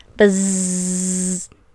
Cries
BEEDRILL.wav